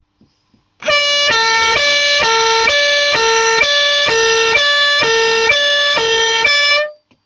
Fisa luchthoorn Impulsound 60 FPI2C 12V Specificaties: - Sirene geluid - 118dB - 200W - 560Hz high tone - 460Hz low tone - 60 cycli per minuut